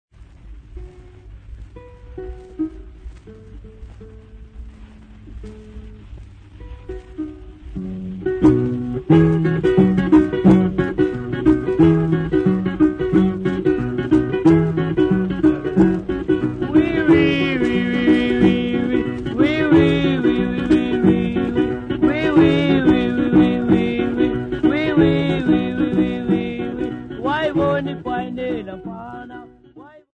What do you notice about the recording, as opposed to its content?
Field recordings Africa Zimbabwe Bulawayo f-rh